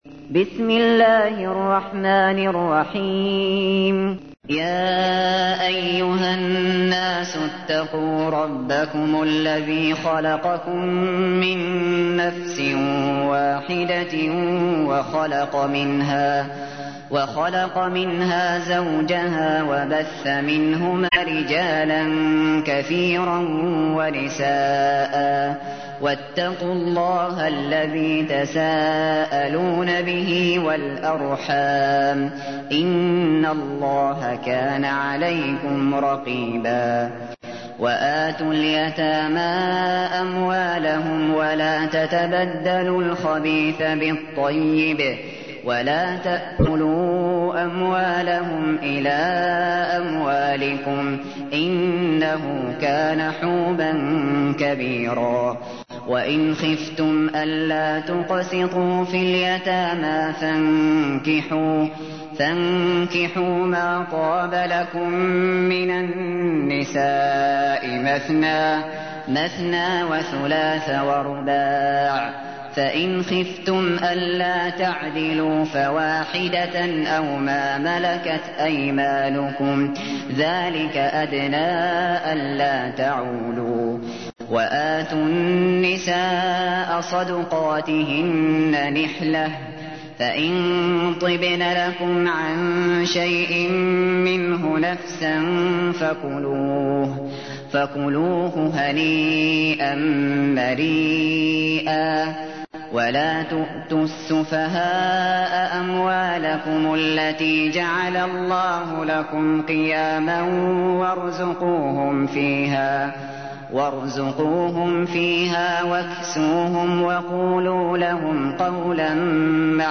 تحميل : 4. سورة النساء / القارئ الشاطري / القرآن الكريم / موقع يا حسين